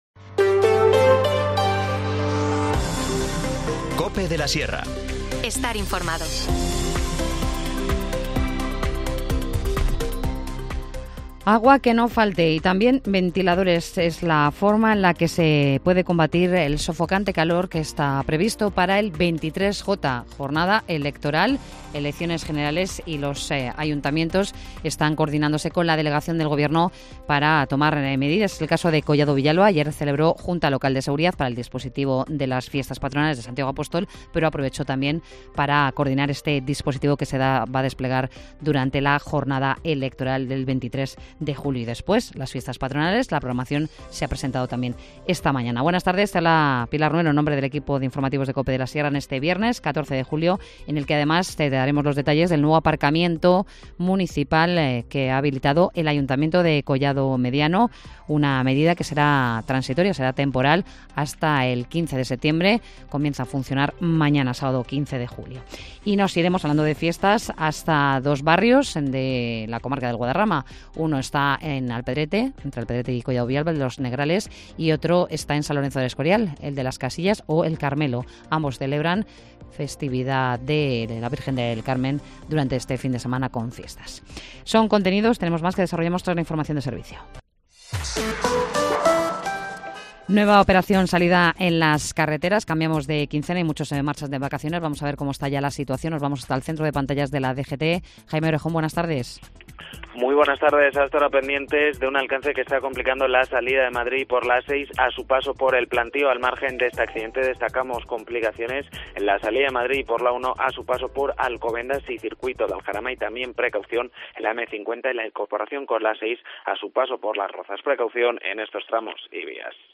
Informativo Mediodía 14 julio